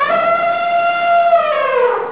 Elephant trumpets
elephant.wav